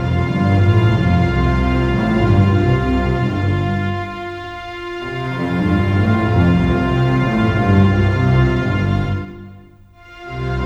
Rock-Pop 22 Stings 01.wav